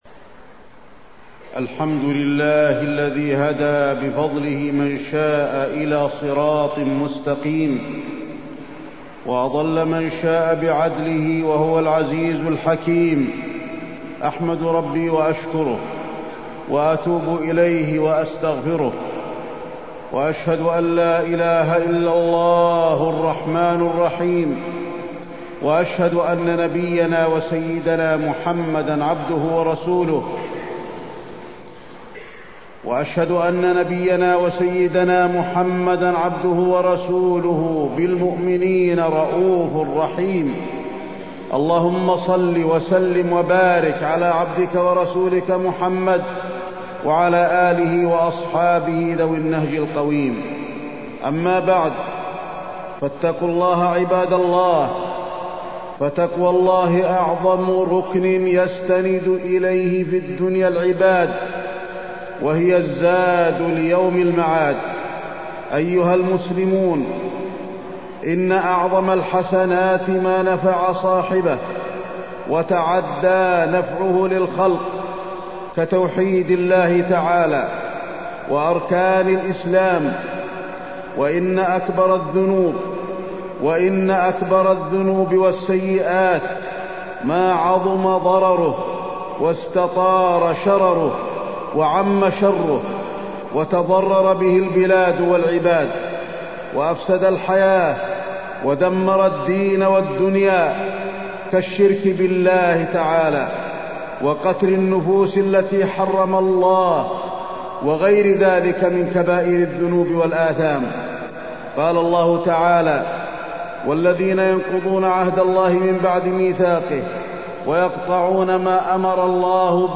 تاريخ النشر ٣٠ ربيع الثاني ١٤٢٥ هـ المكان: المسجد النبوي الشيخ: فضيلة الشيخ د. علي بن عبدالرحمن الحذيفي فضيلة الشيخ د. علي بن عبدالرحمن الحذيفي الخوارج